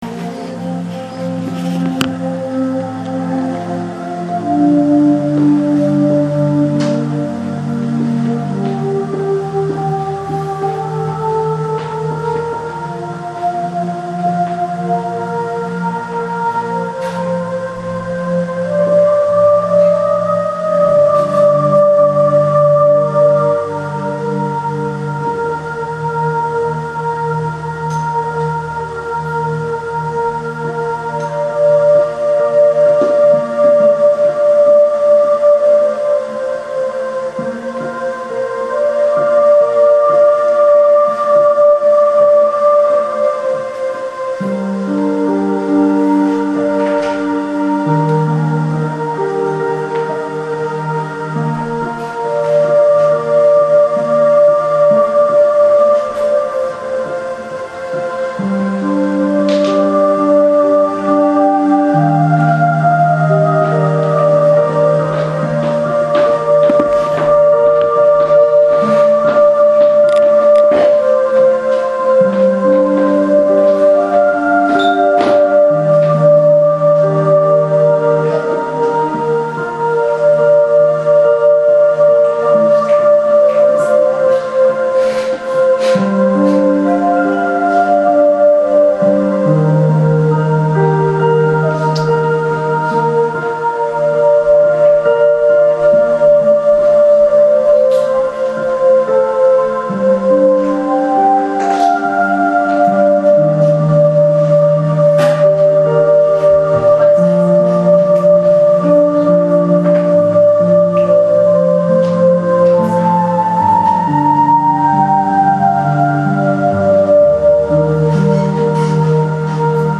主日恩膏聚会录音（2015-06-21）